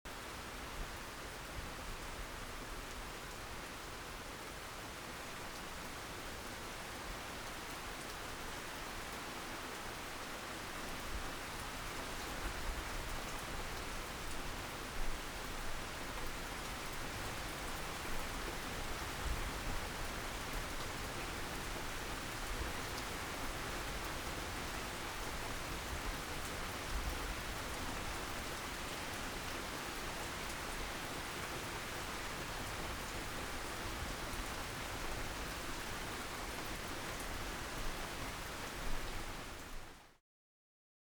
rain
Rain, Light With Plops On Leaves